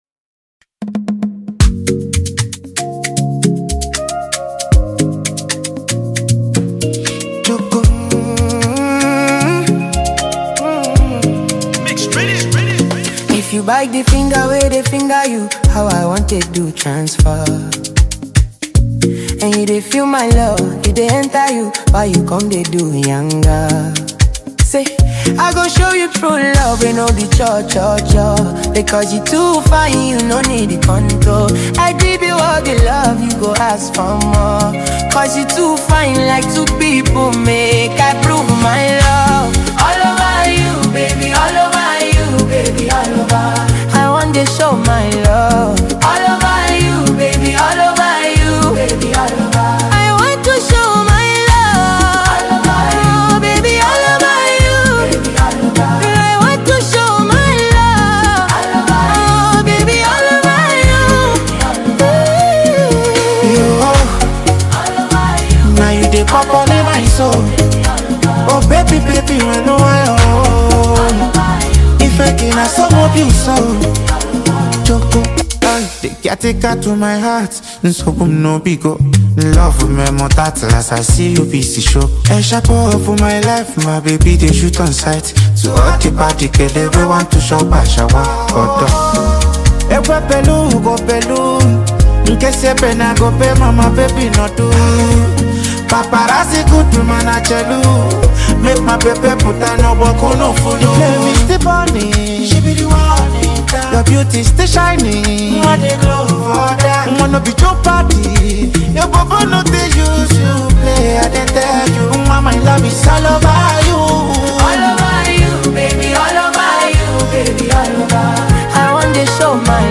Calm, steady, and easy to come back to.